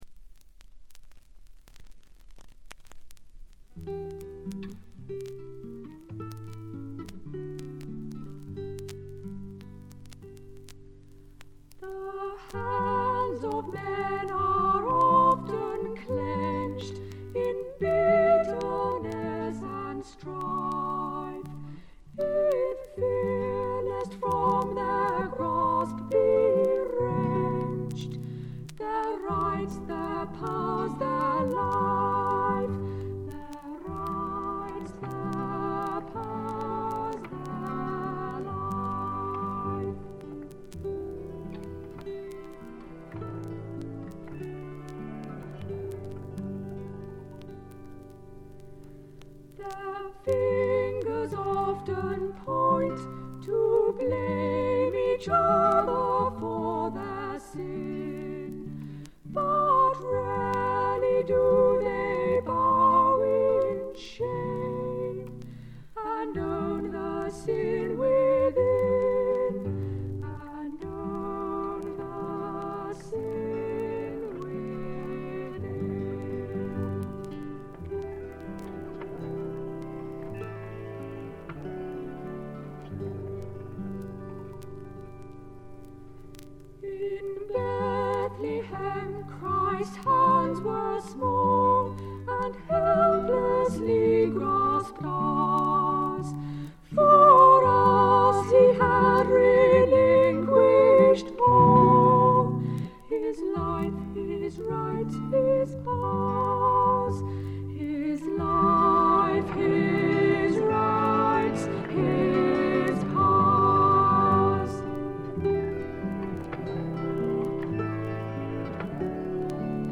細かなバックグラウンドノイズ、チリプチは出ていますが鑑賞を妨げるようなノイズはありません。
本人たちのオートハープとギターのみをバックに歌われる美しい歌の数々。純粋で清澄な歌声にやられてしまいますよ。
試聴曲は現品からの取り込み音源です。